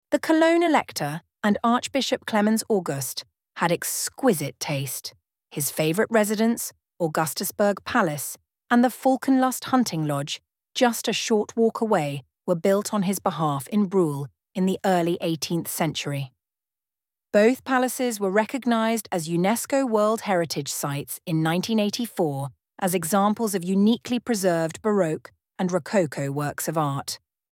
audioguide-english-br-hl-palaces.mp3